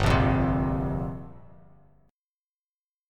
Gm11 Chord
Listen to Gm11 strummed